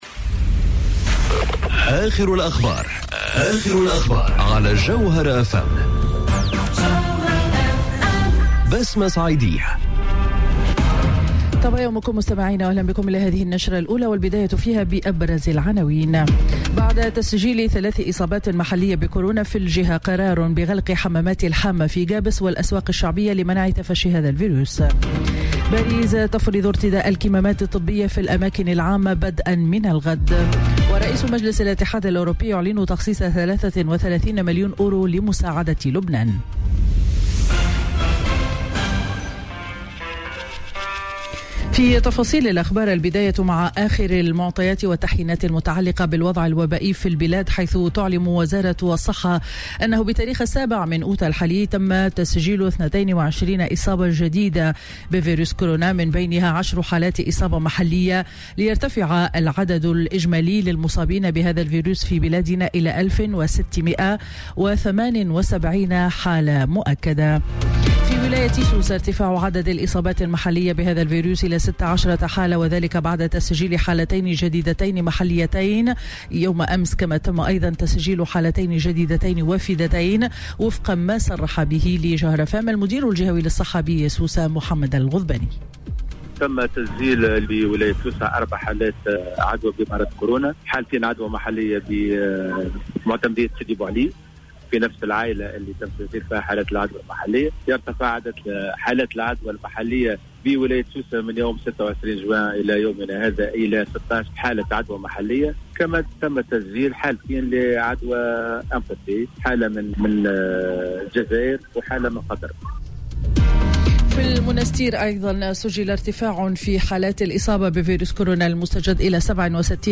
نشرة أخبار السابعة صباحا ليوم الأحد 09 أوت 2020